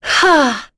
Lorraine-Vox_Attack1.wav